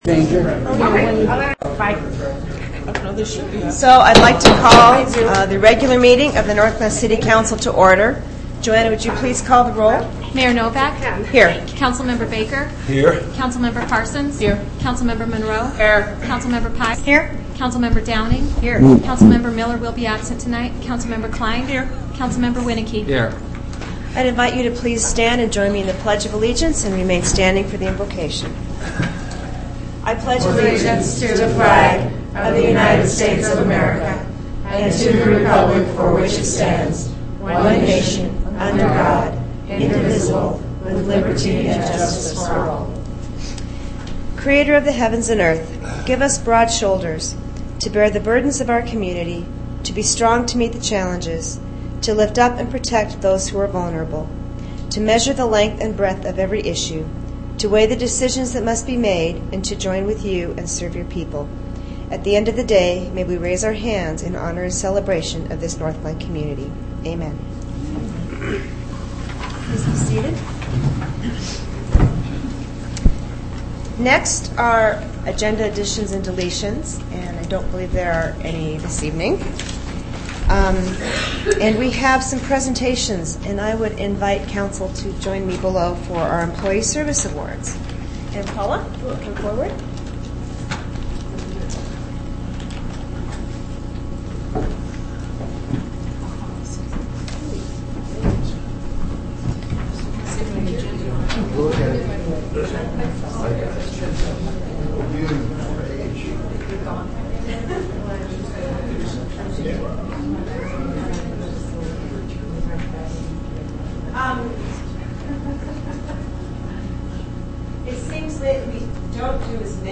Audio of City Council on 2009-01-22